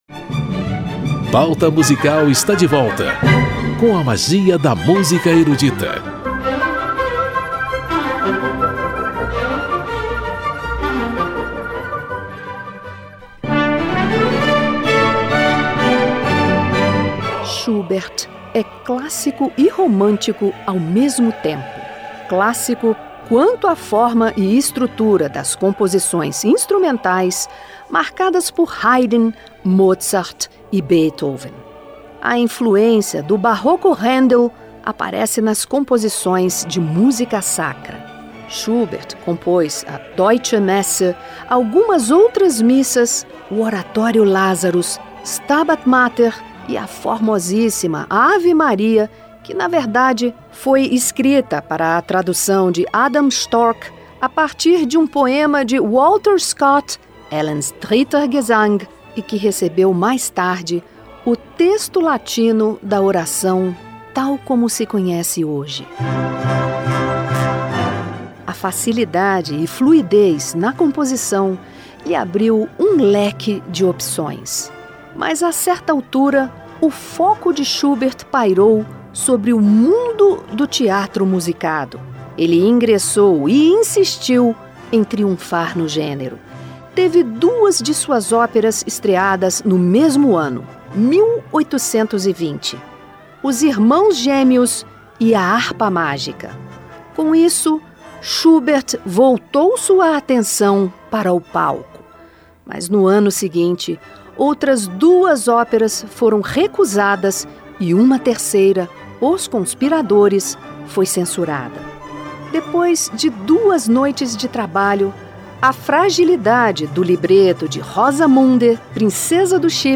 Danças, canções, trio e quinteto de Franz Schubert.